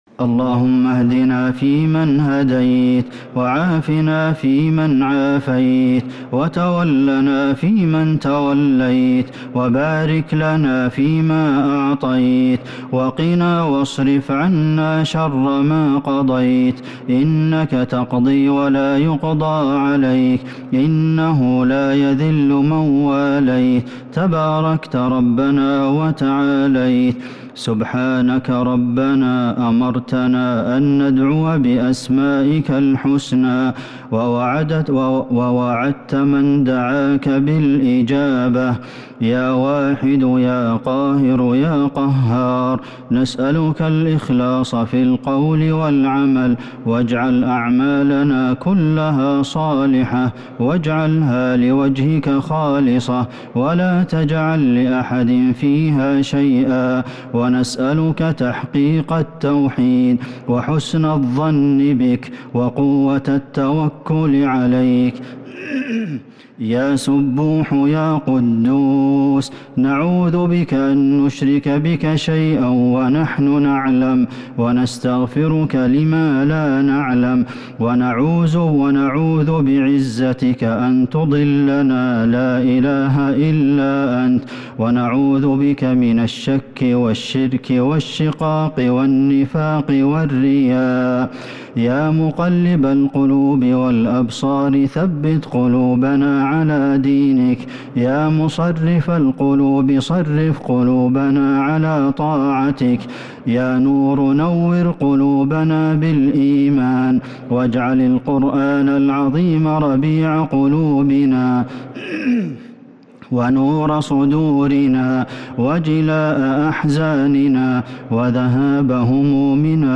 دعاء القنوت ليلة 17 رمضان 1443هـ | Dua for the night of 17 Ramadan 1443H > تراويح الحرم النبوي عام 1443 🕌 > التراويح - تلاوات الحرمين